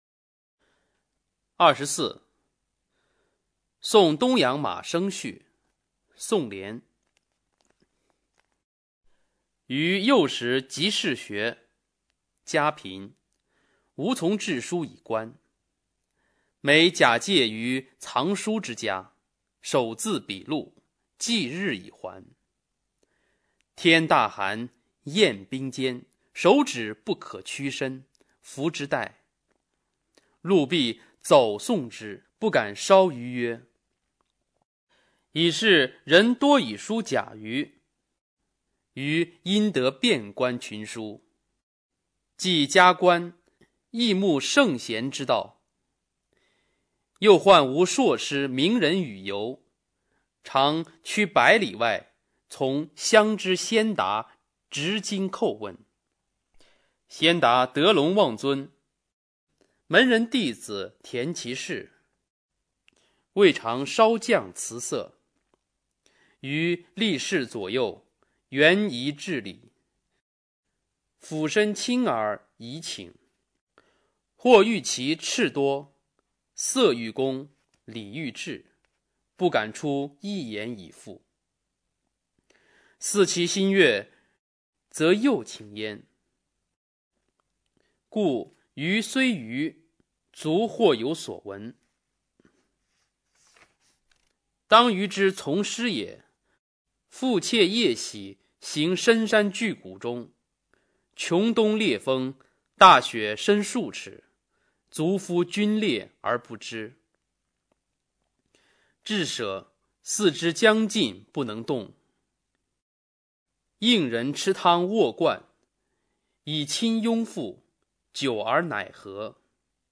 宋濂《送东阳马生序》原文和译文（含mp3朗读）